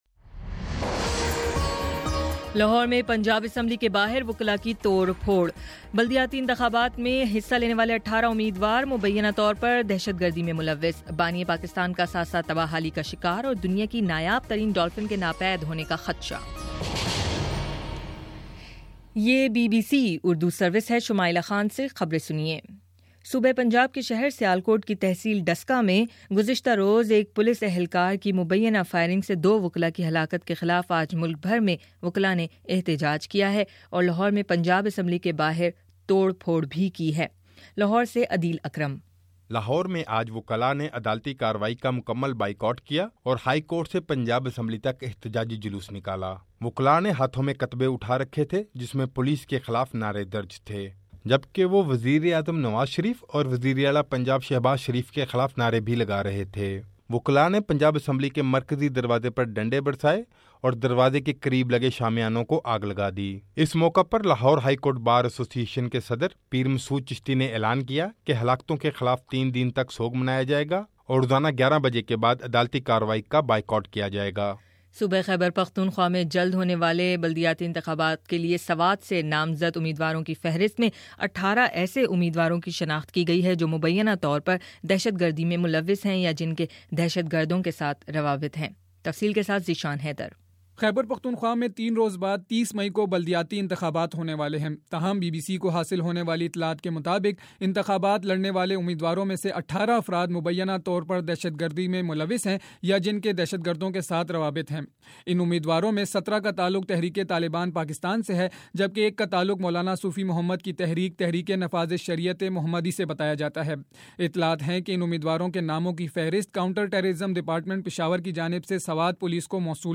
مئی 26: شام سات بجے کا نیوز بُلیٹن